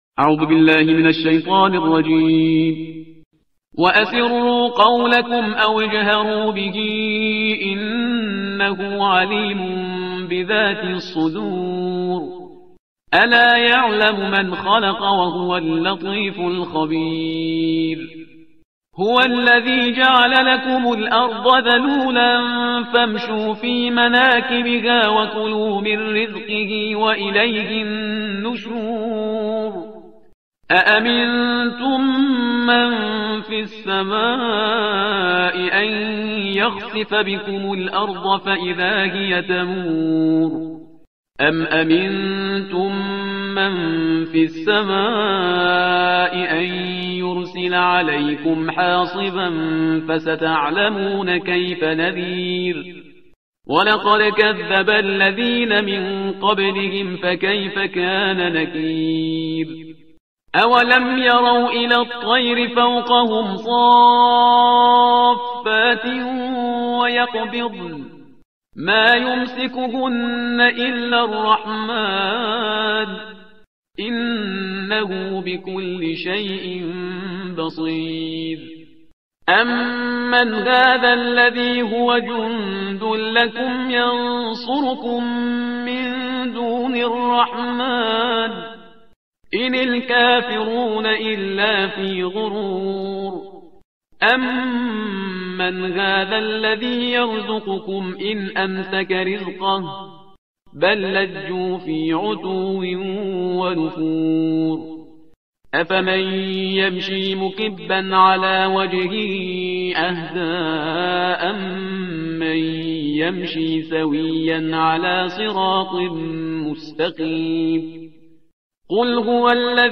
ترتیل صفحه 563 قرآن – جزء بیست و نهم